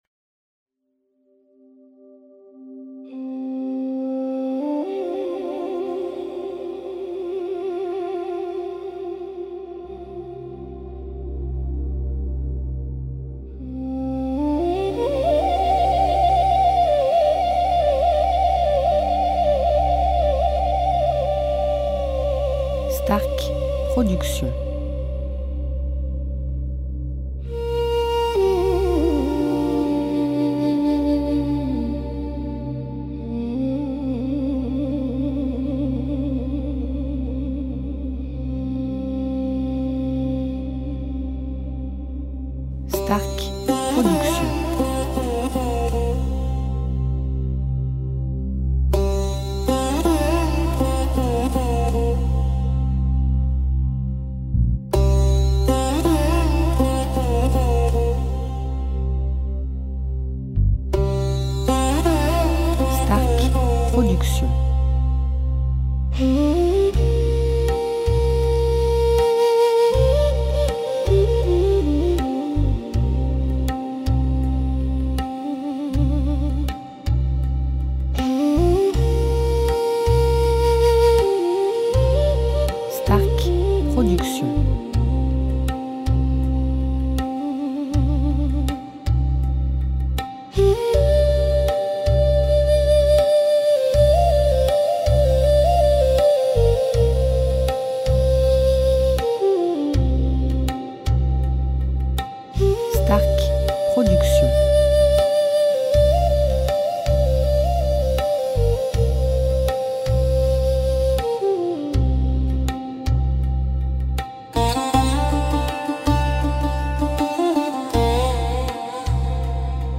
style Ayurvédique durée 1 heure